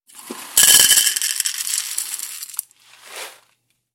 Звук пересыпания крупы из пакета в стеклянную миску